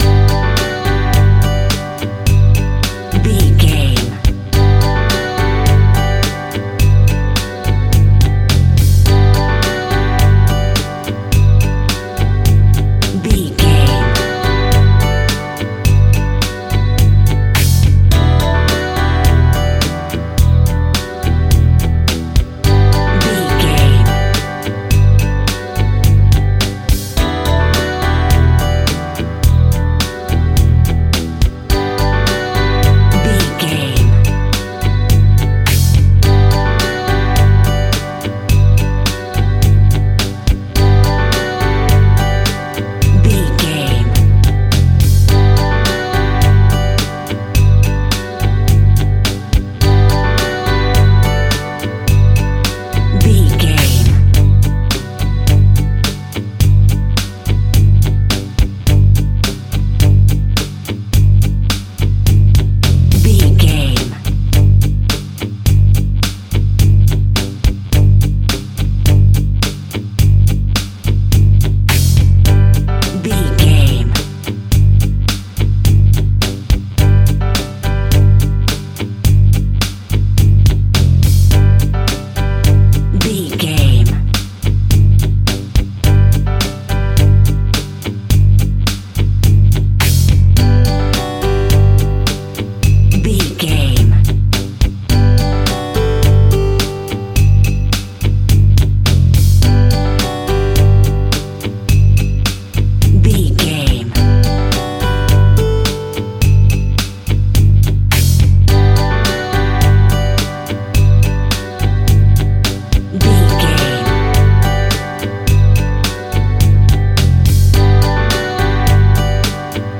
Bright and Youthful.
Ionian/Major
Funk
groove
jazz funk
funky instrumentals
energetic
synths
drums
bass
guitar
piano